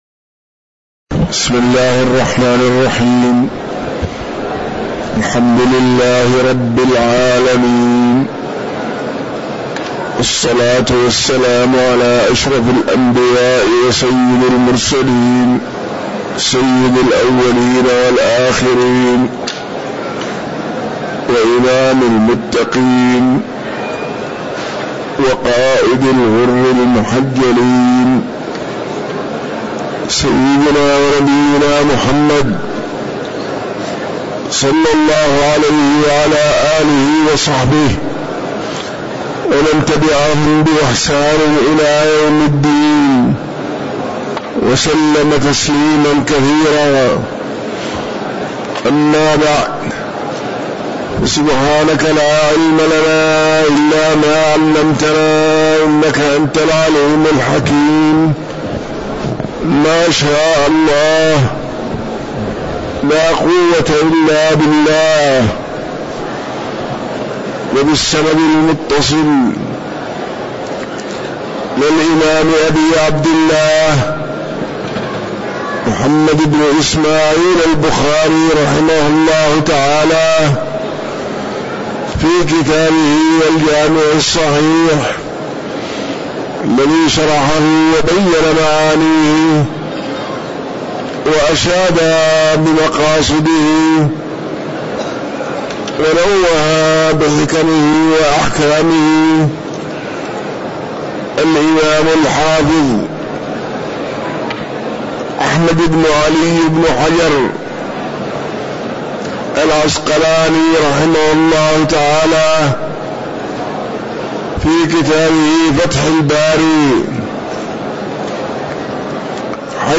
تاريخ النشر ٢٤ جمادى الآخرة ١٤٤٤ هـ المكان: المسجد النبوي الشيخ